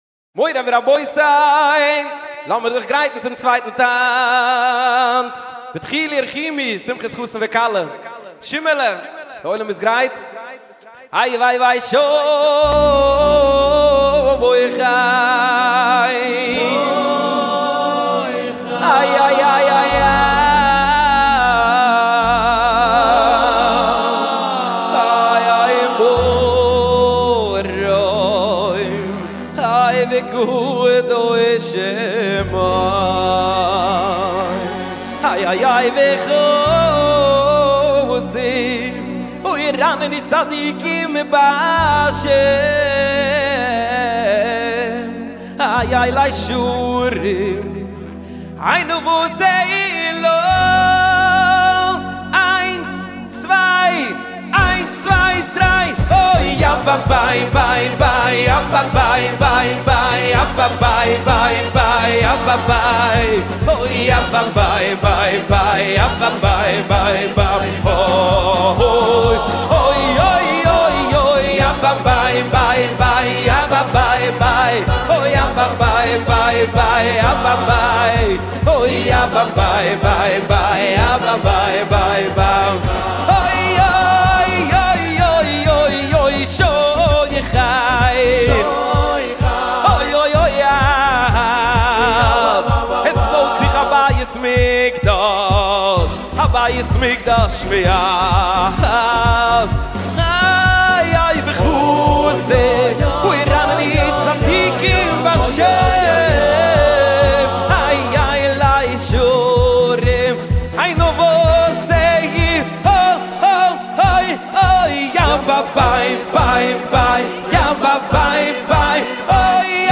בבקשה (זה לא קליפ רשמי אלא מתוך חתונה)